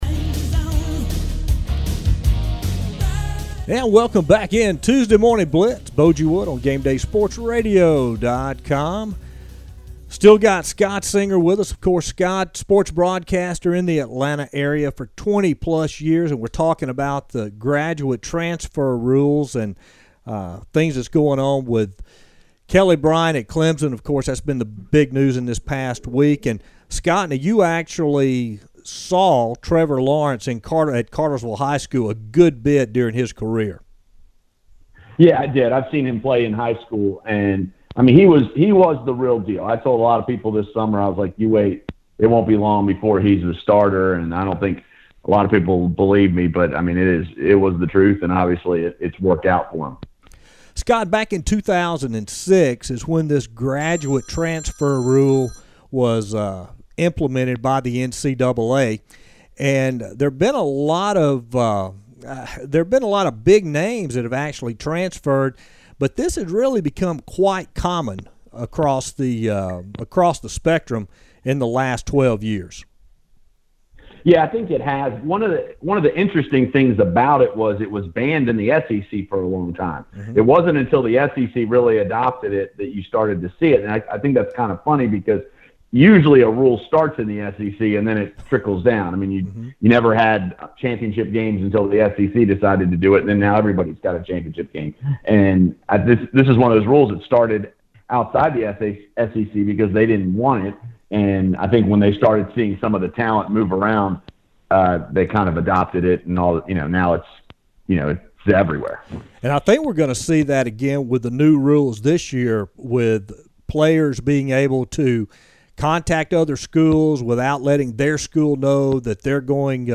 Guest Interviews